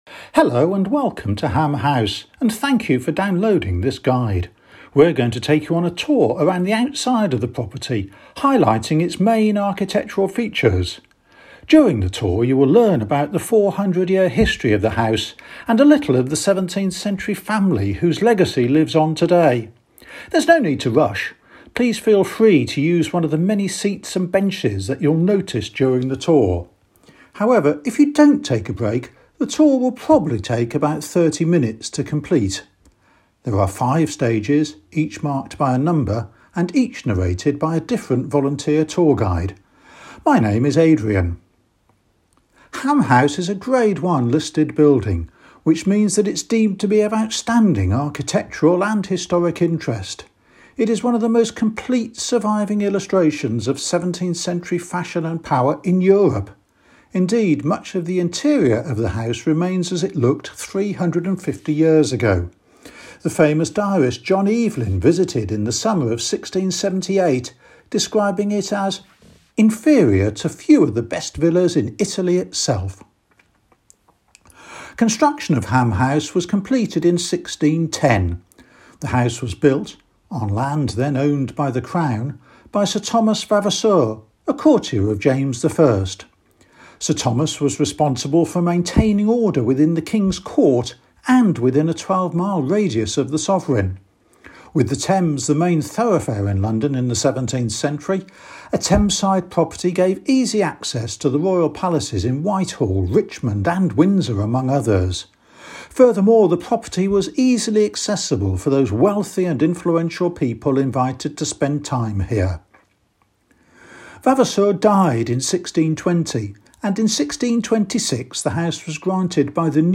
Ham House - House Audio Tour Stop 1 - Welcome to Ham House